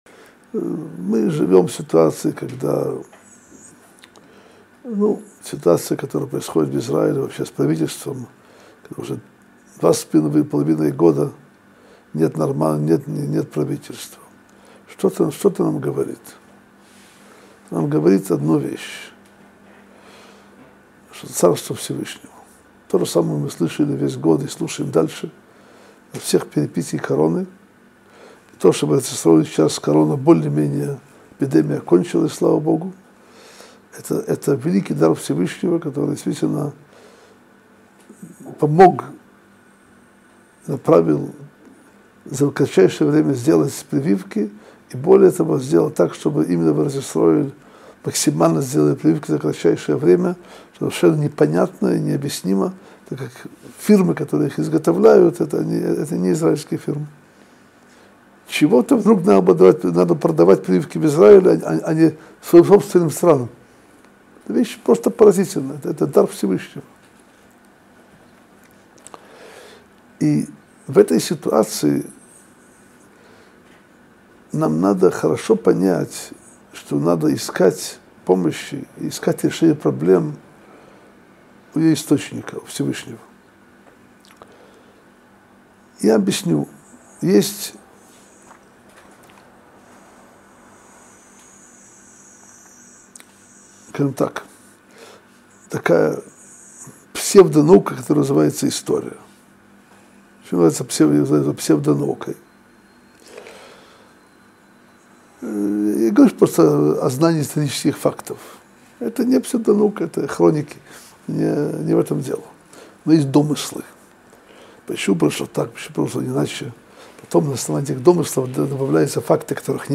Содержание урока: